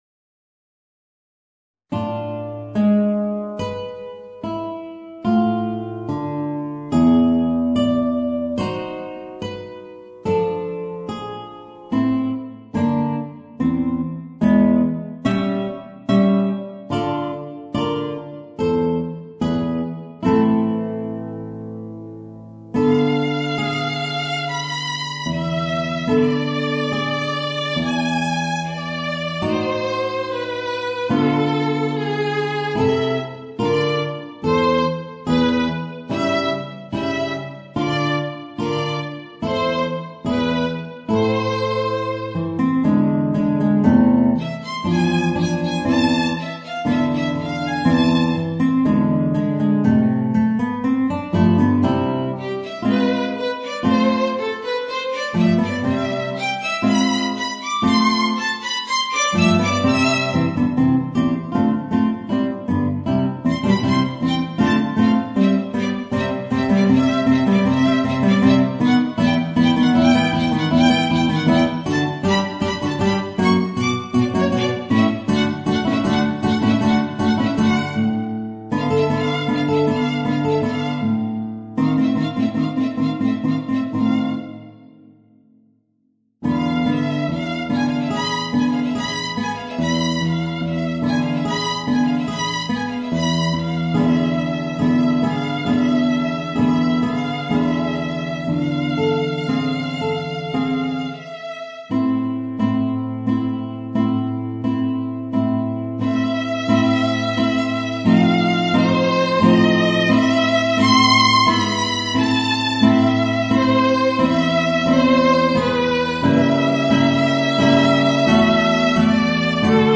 Voicing: Guitar and Violin